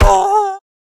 Minecraft Version Minecraft Version snapshot Latest Release | Latest Snapshot snapshot / assets / minecraft / sounds / mob / wolf / grumpy / hurt1.ogg Compare With Compare With Latest Release | Latest Snapshot
hurt1.ogg